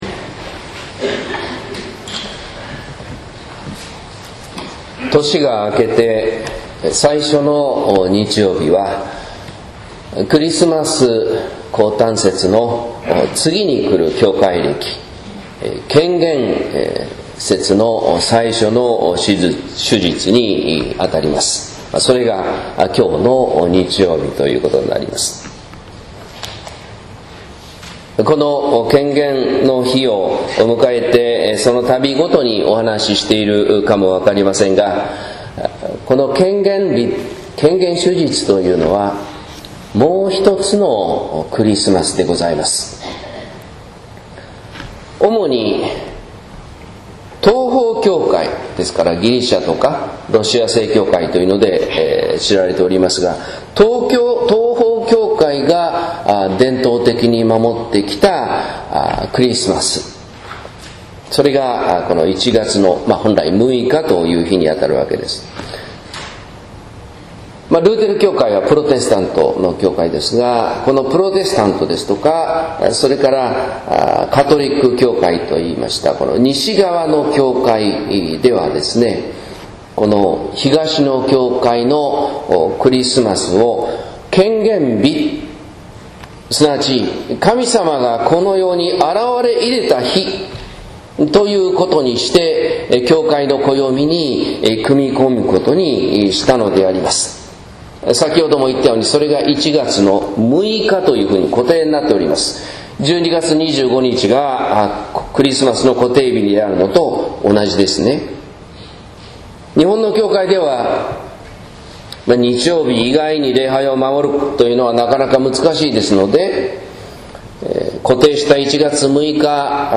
説教「幼子に従う」（音声版）